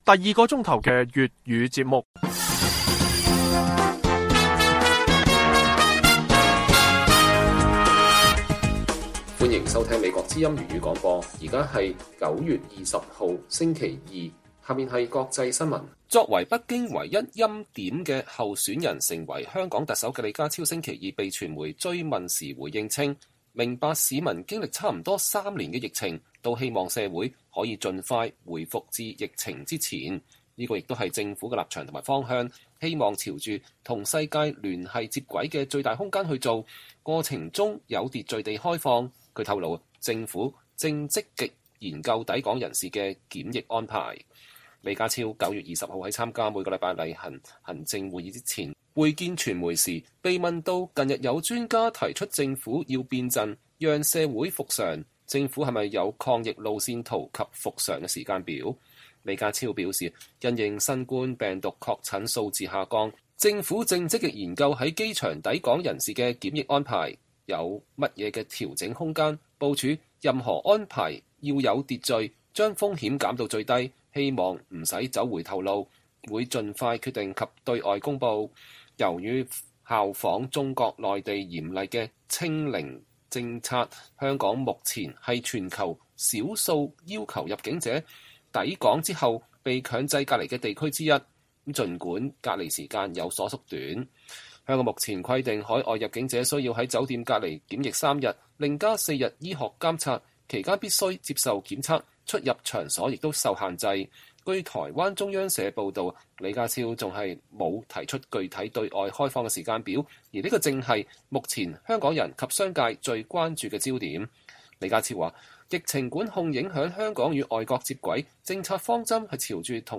粵語新聞 晚上10-11點: 香港迫於壓力研究調整海外抵港人士檢疫安排